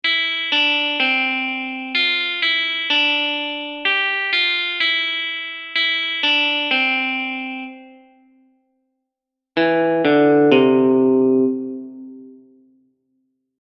Try to play it starting on different notes but stay in the scale (C major: C-D-E-F-G-A-B-C).
Other options are A-G-F, or B-A-G, or C-B-A. What happens if you make a melody consisting of this motive started on different notes?
three-blind-mice-transposed-opening-song.mp3